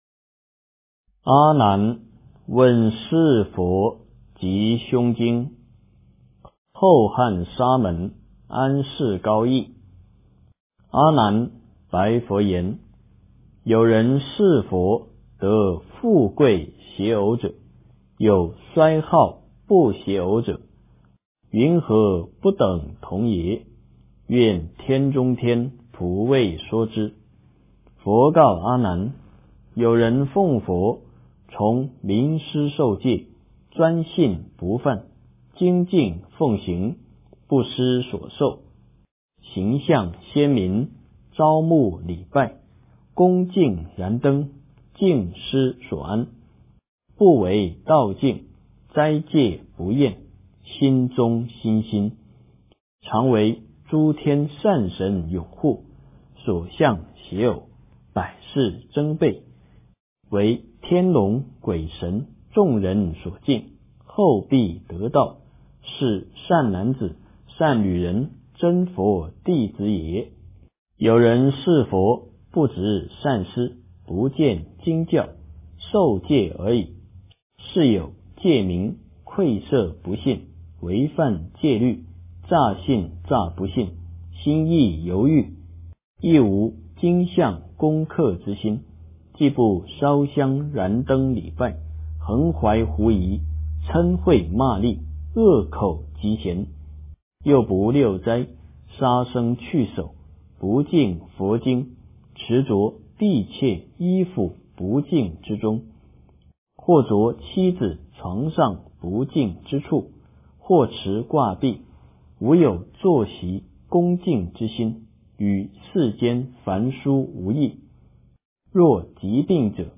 诵经
佛音 诵经 佛教音乐 返回列表 上一篇： 大势至菩萨念佛圆通章-仪轨 下一篇： 佛说阿弥陀经 相关文章 念三宝--圆满自在组 念三宝--圆满自在组...